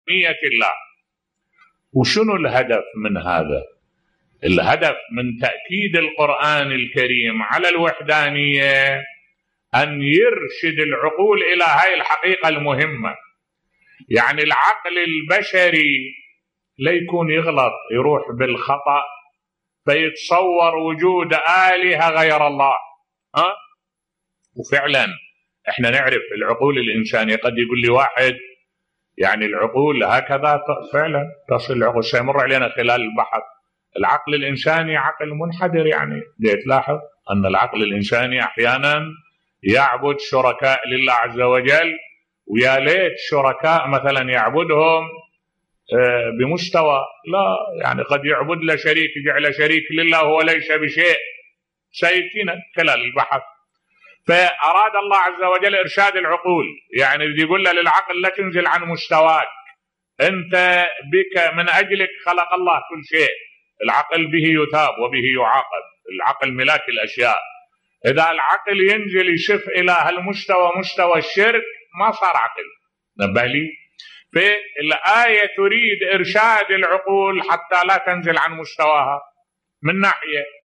ملف صوتی خطورة الشرك على العقل البشري بصوت الشيخ الدكتور أحمد الوائلي